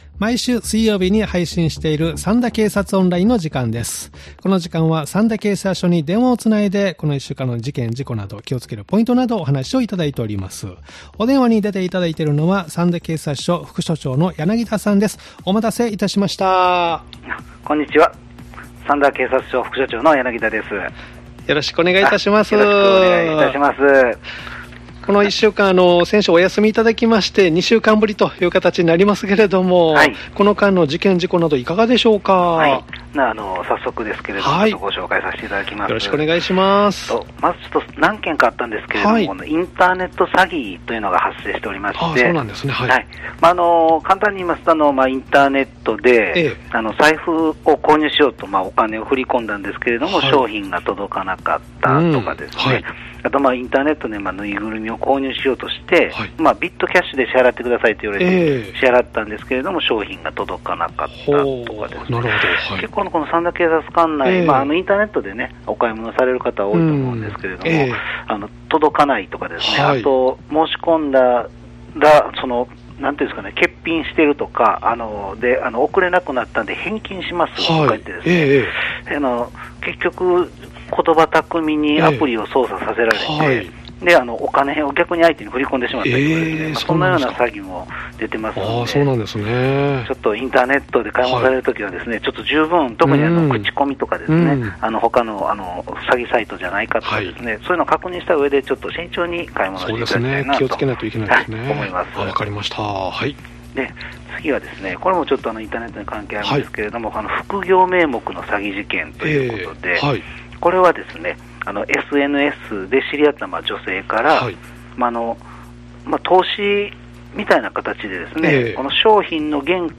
三田警察署に電話を繋ぎ、一週間の事件事故、防犯情報、警察からのお知らせなどをお聞きしています（再生ボタン▶を押すと番組が始まります）